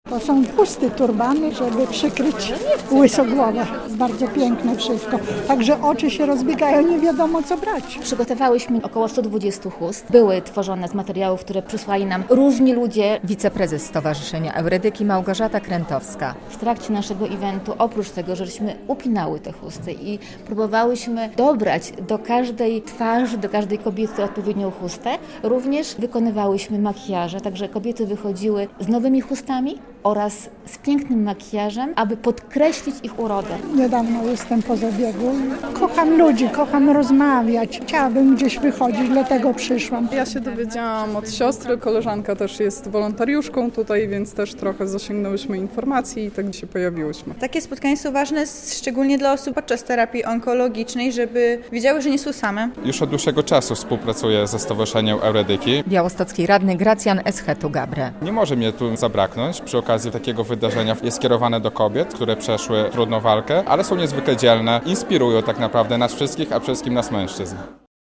"Urocza Ty" - akcja Stowarzyszenia Eurydyki - relacja